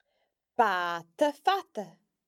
Listen out for the à sound again in a short phrase: